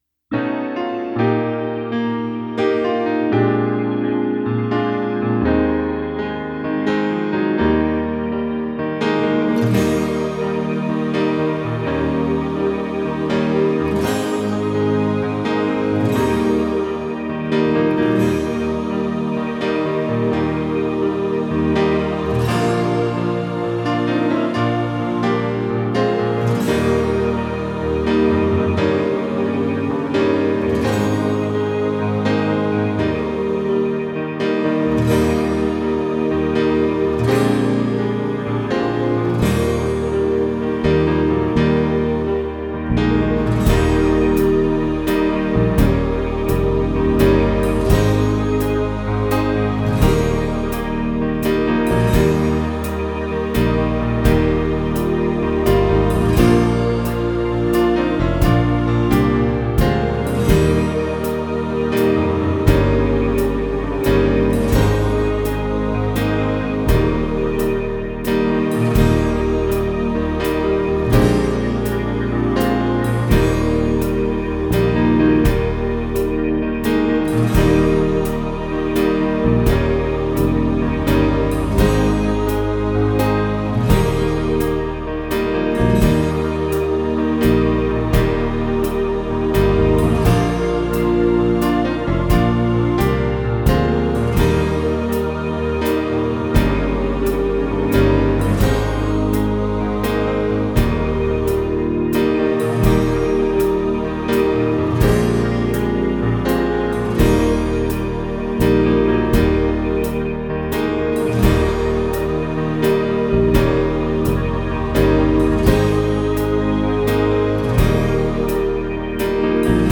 Traditional_Amazing_Gracemusic-generic.mp3